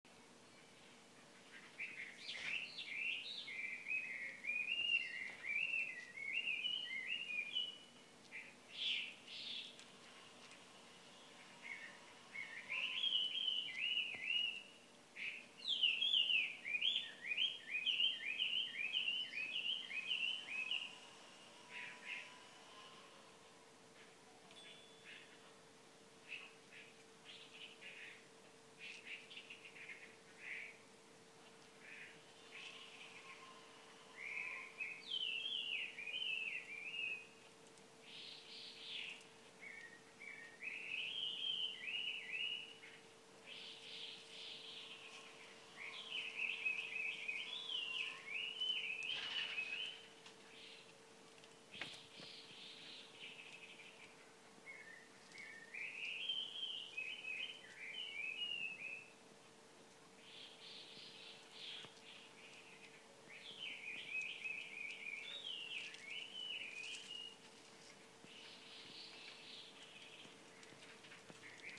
春の音。
鳥の音と共に。